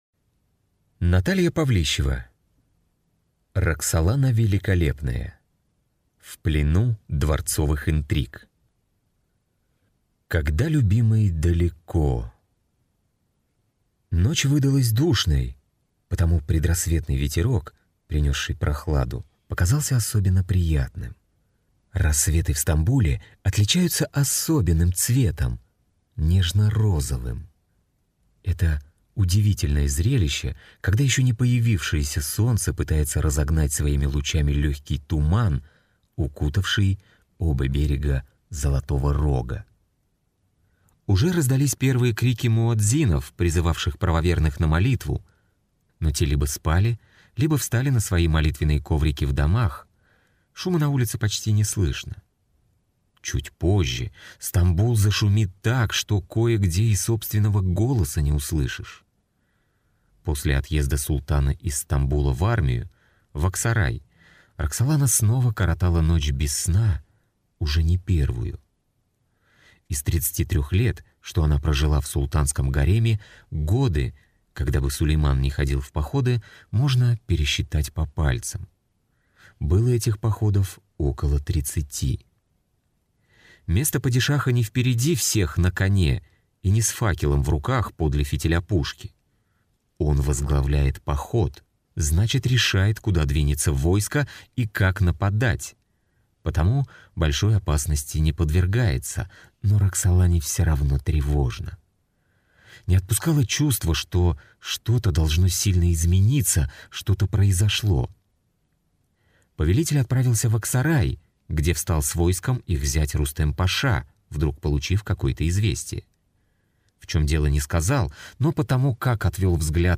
Аудиокнига Роксолана Великолепная. В плену дворцовых интриг | Библиотека аудиокниг